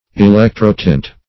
Electro-tint \E*lec"tro-tint`\, n. (Fine Arts)